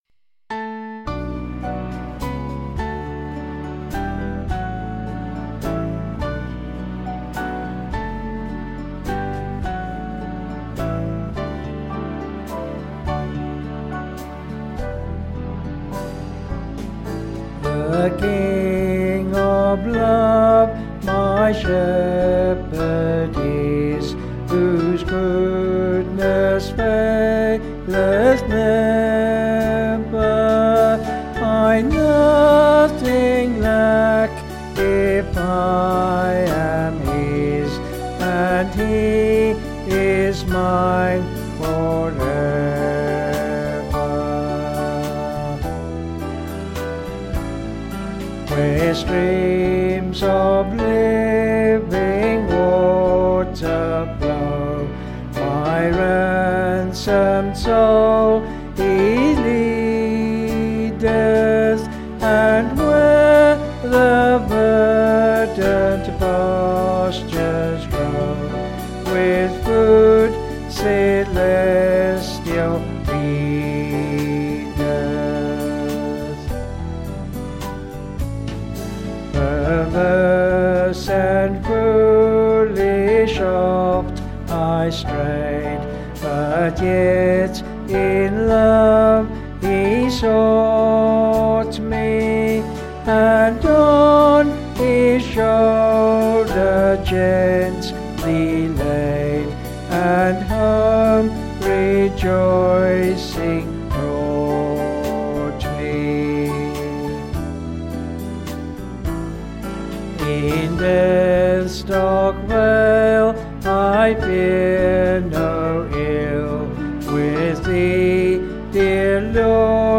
(BH)   6/D-Eb
Vocals and Band   264.6kb Sung Lyrics